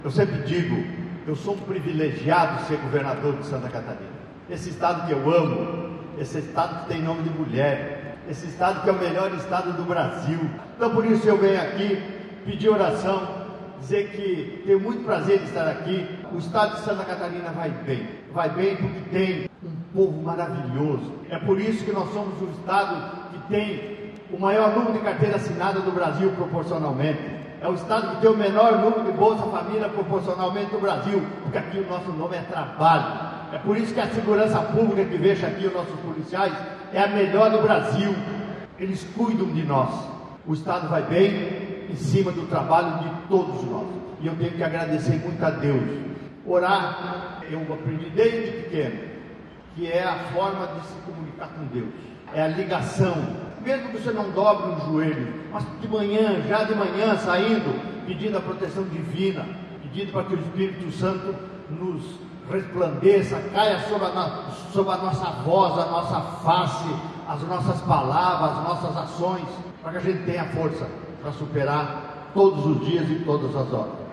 SONORA – Jorginho Mello participa da abertura do CAP 25 em Balneário Camboriú
O governador Jorginho Mello participou nesta quinta-feira, 1º de maio, da abertura do Congresso de Avivamento Profético – CAP 25, no Centro de Eventos de Balneário Camboriú.
Durante a cerimônia, o governador destacou a importância de iniciativas que unem fé, valores e transformação social:
SECOM-Sonora-Governador-Congresso-de-Avivamento-Profetico.mp3